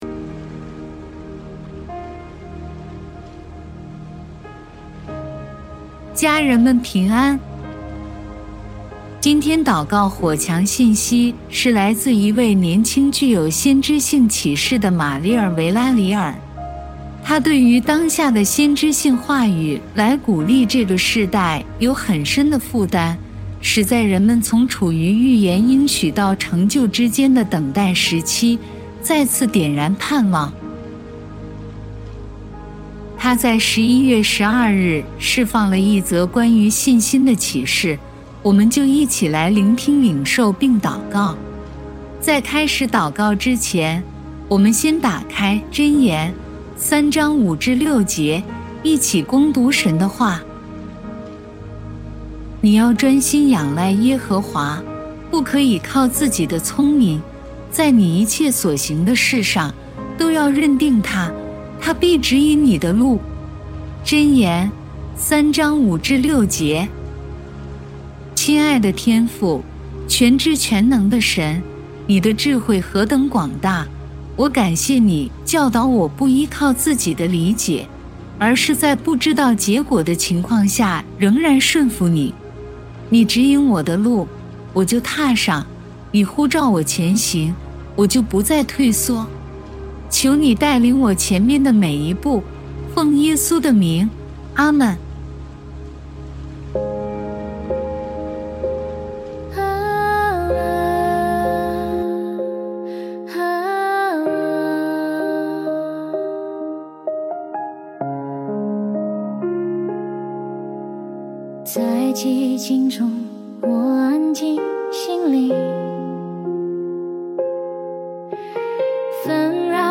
让我们一起来祷告： 亲爱的天父， 今天我们满心的向你献上感谢。